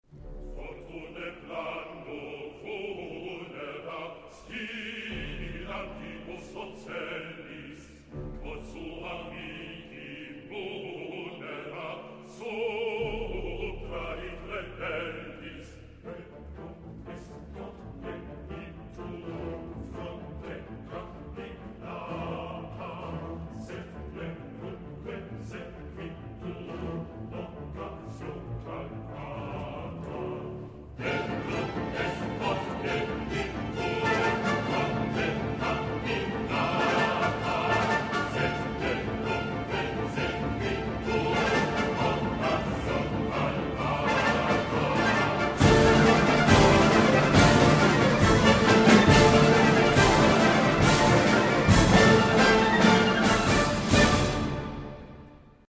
去年的現場音樂會錄音
這個版本的合唱部分，令人喜歡
柔軟的聲音